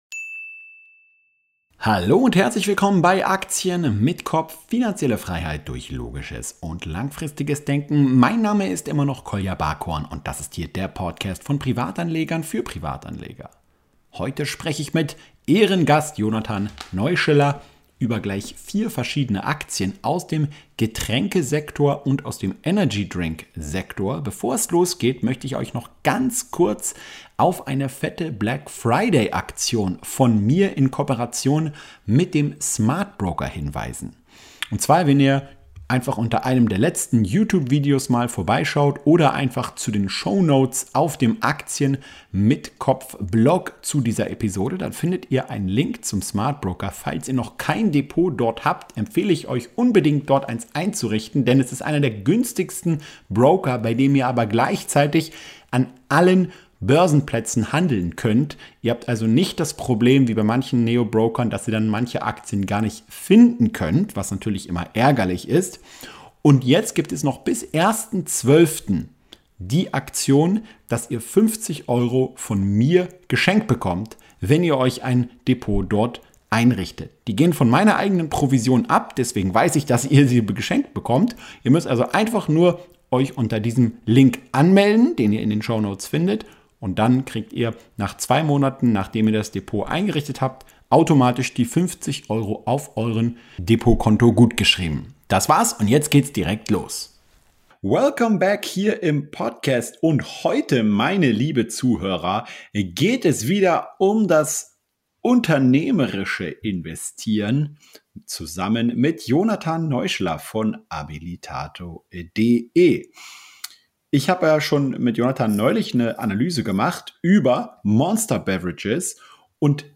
Wir sprechen heute über Coca-Cola, PepsiCo, Celsius Holdings und Monster Beverage! Dabei geht es vor allem um einen Branchenüberblick und die unterschiedlichen Charakteristika der einzelnen Aktien. Viel Spaß beim Gespräch.